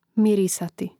mirìsati mirisati